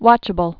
(wŏchə-bəl)